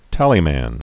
(tălē-măn)